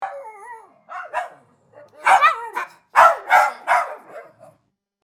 Dog Fight Efecto de Sonido Descargar
Dog Fight Botón de Sonido